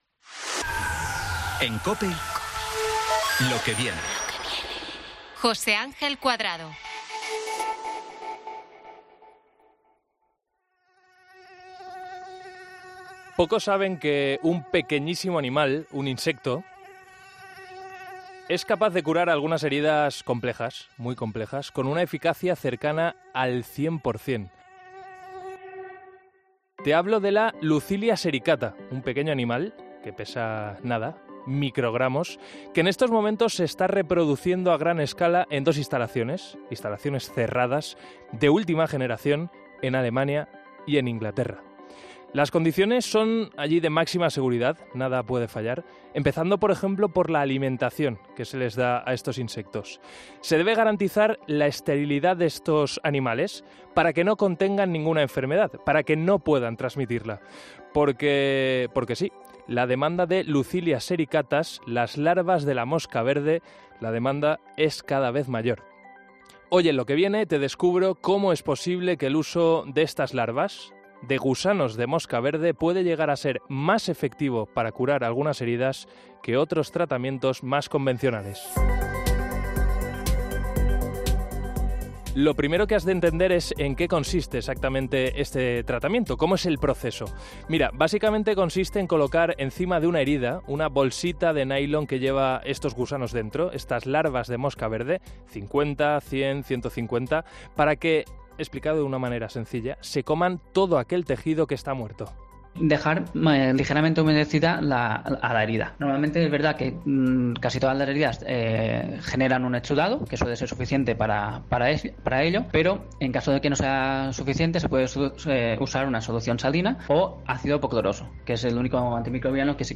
Te presentamos a tres personas que saben muy bien cómo se utilizan las larvas de mosca para curar heridas